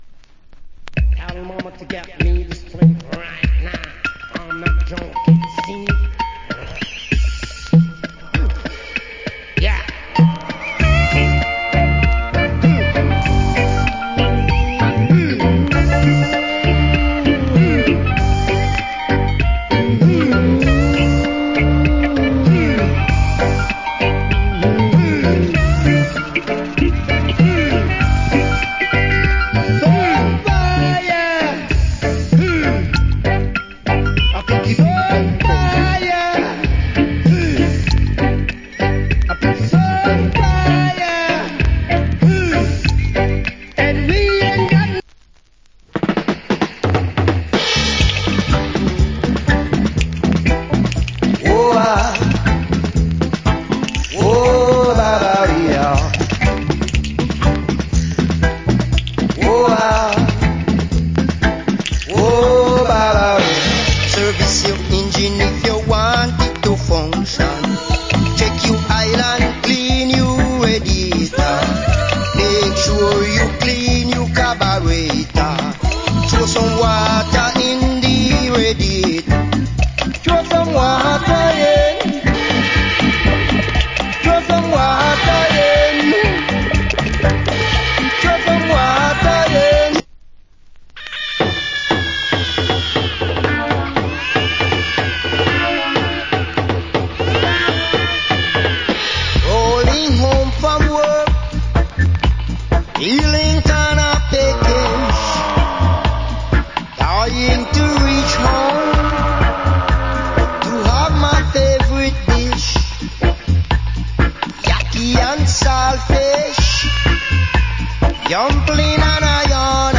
Nice Roots & Reggae